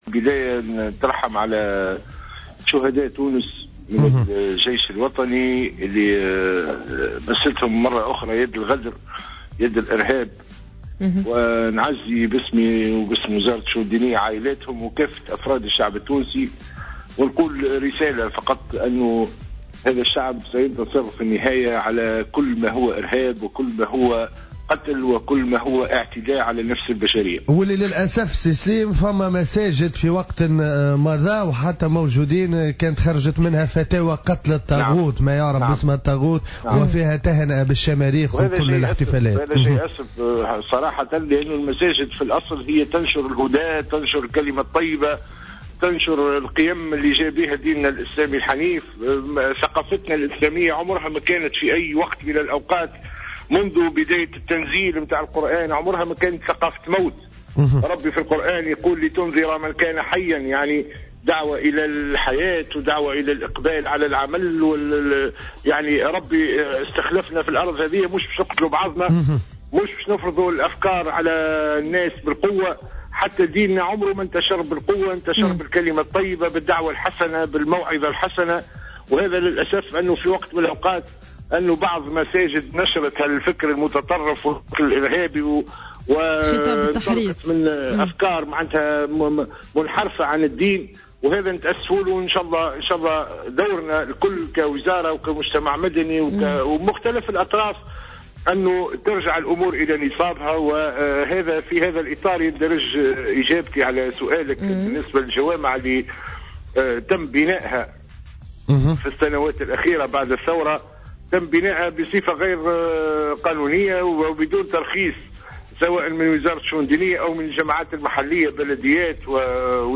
في مداخلة له اليوم في برنامج "صباح الورد"